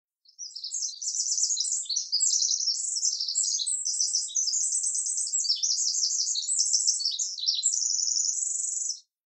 Stimme Zaunkönig
Zaunkoenig.mp3